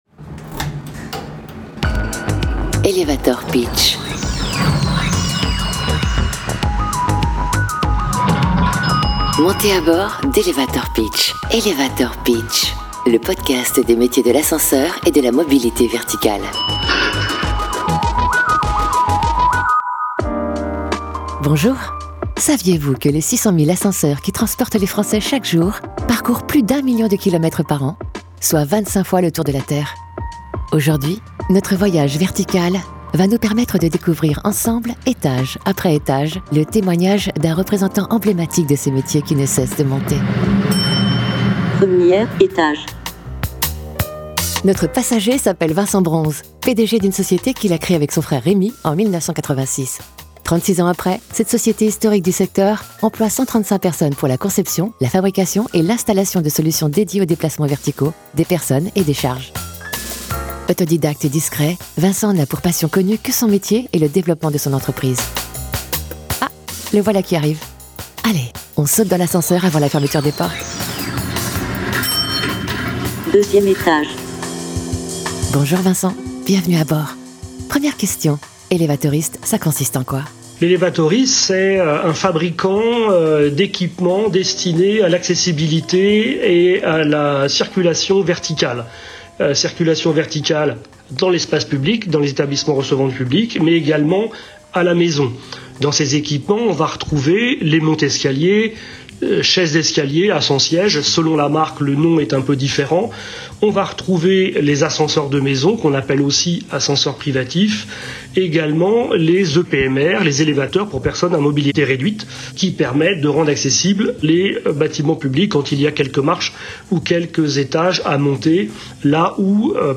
La Fédération des Ascenseurs lance ses podcasts témoignages de professionnels de la mobilité verticale, en valorisant leur parcours formation et/ou professionnel avec une interaction sur l’attachement à leur métier et/ou la réalité de ce dernier.